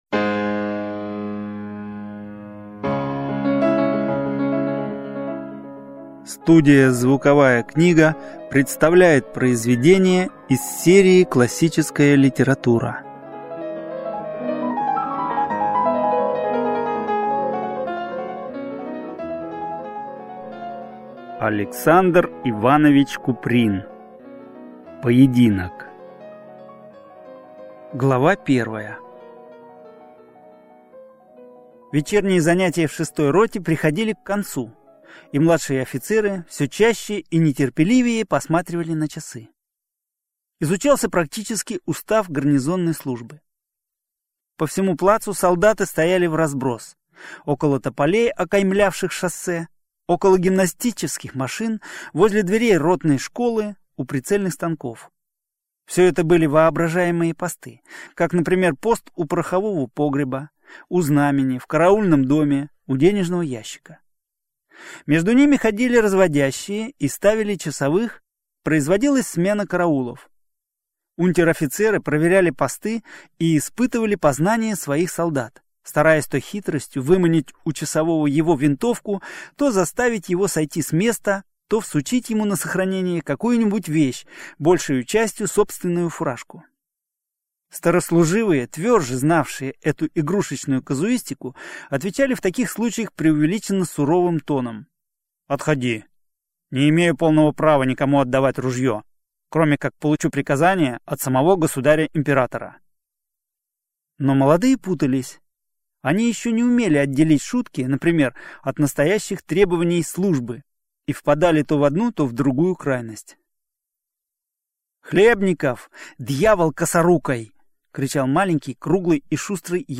Аудиокнига Поединок | Библиотека аудиокниг